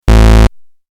8-Bit Error Sound Effect
Description: 8-bit error sound effect. Game error / fail sound effect.
8-bit-error-sound-effect.mp3